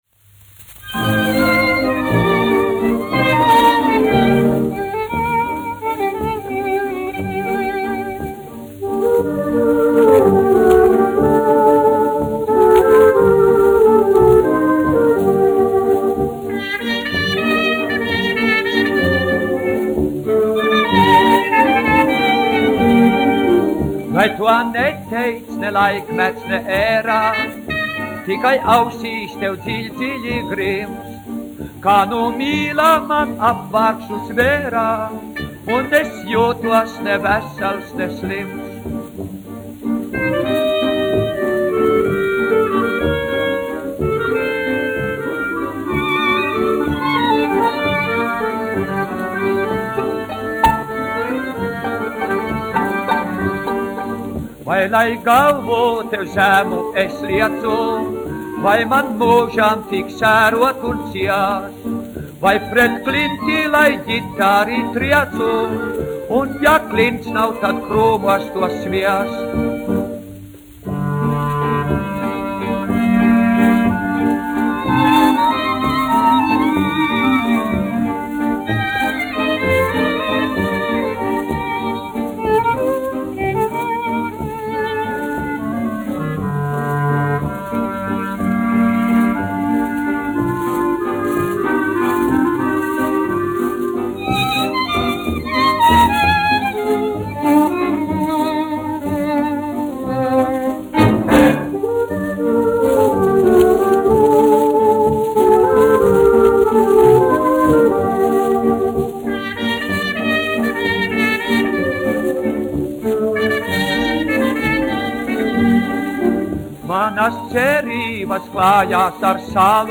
dziedātājs
1 skpl. : analogs, 78 apgr/min, mono ; 25 cm
Latvijas vēsturiskie šellaka skaņuplašu ieraksti (Kolekcija)